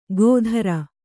♪ gōdhara